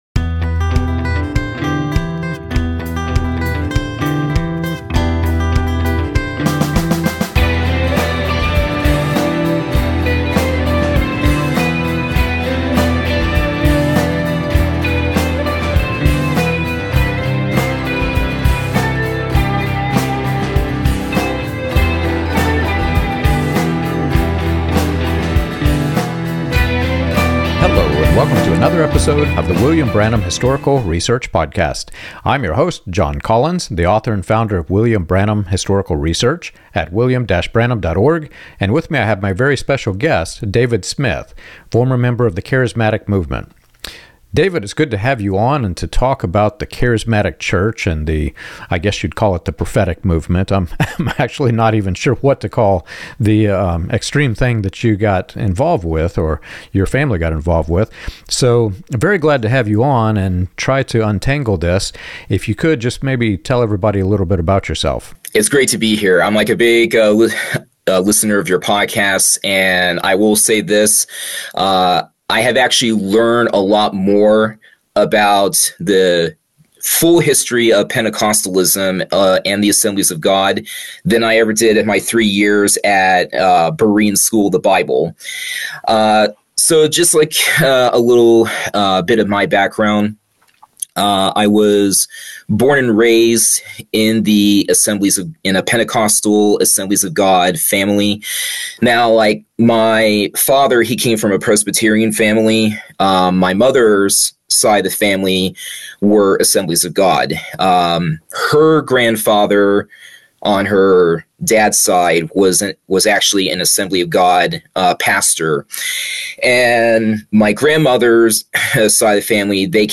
This discussion explores Pentecostal history, prophetic movements, and the warning signs of personality-driven ministries.